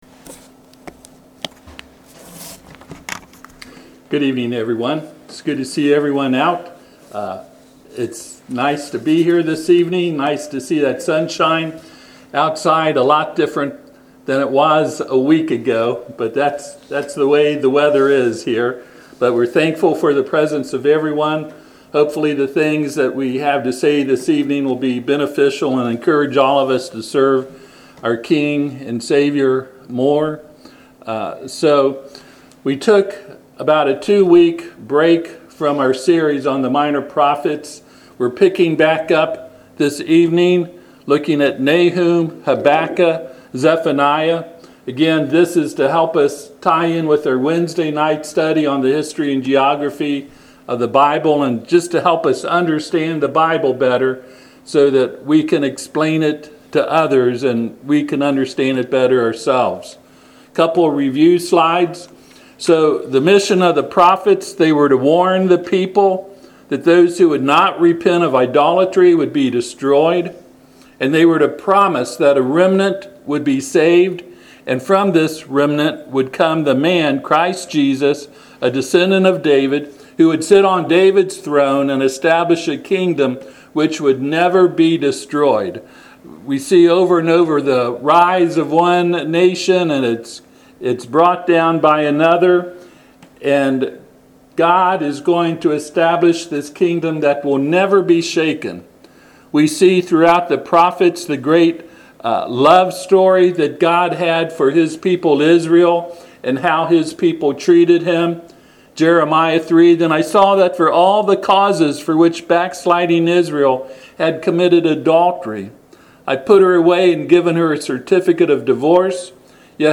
Passage: Habakkuk 3:18 Service Type: Sunday PM